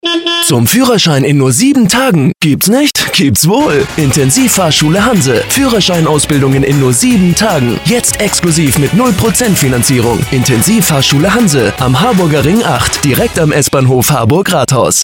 Der Sender Radio Energy veranstaltete ein Summer-Special, bei dem wir als Partner mitwirken durften. Hören Sie sich hier unseren fertig gestellten Radiospot an.
radioenergyspot.mp3